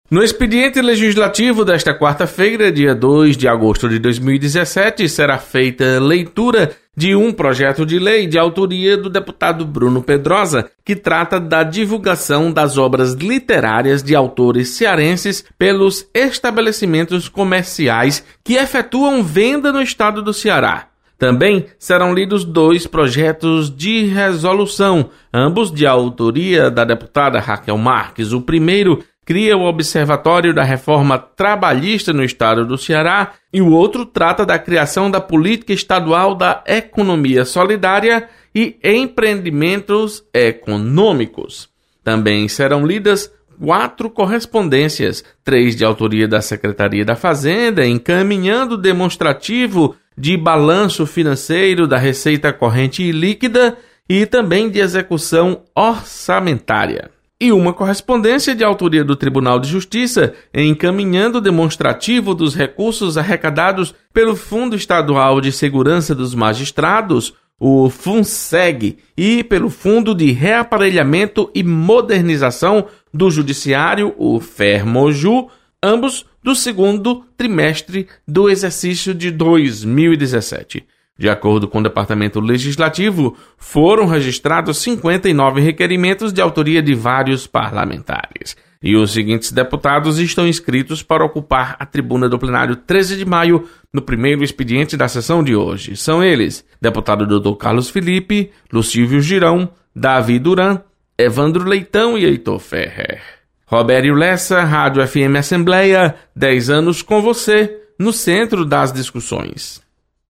Você está aqui: Início Comunicação Rádio FM Assembleia Notícias Expediente